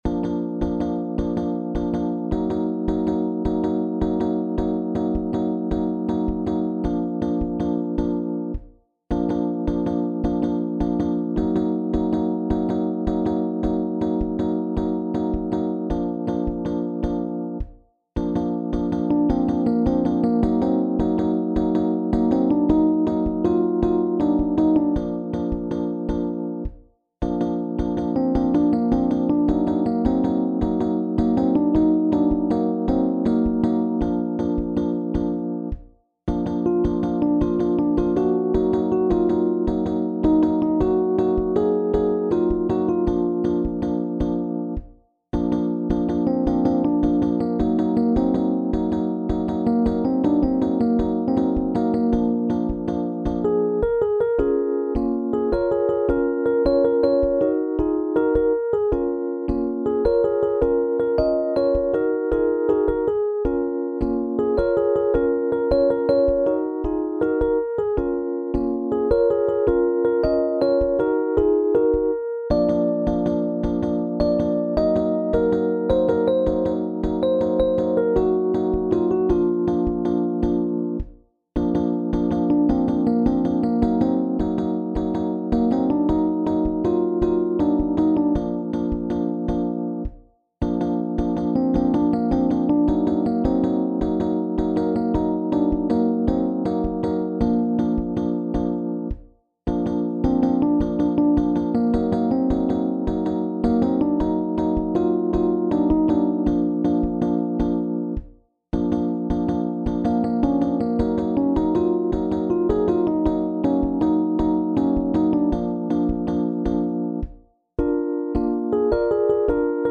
SSA met mezzo-solo | SAB met solo
Een overrompelend lied van een nieuwe zangeres